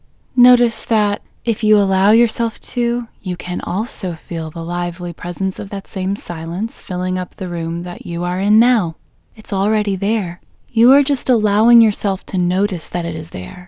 WHOLENESS English Female 7